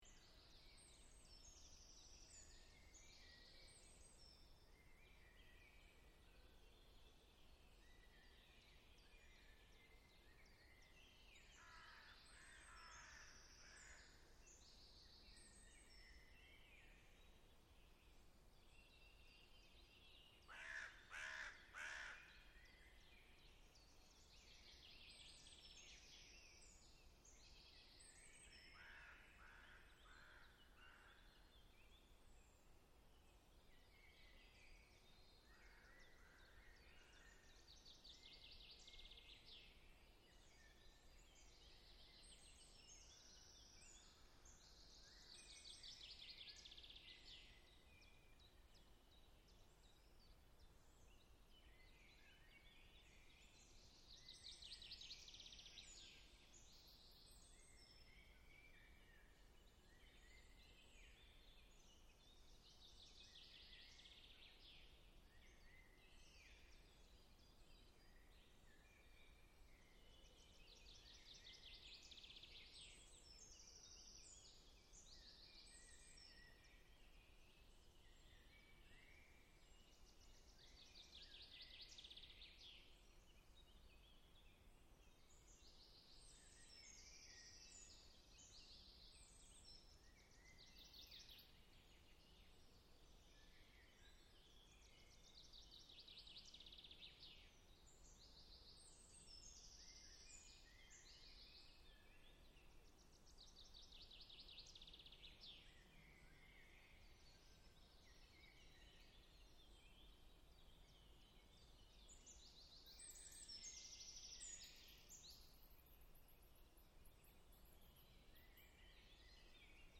In the forest at night
This audio provides a gentle breeze and many chirping birds.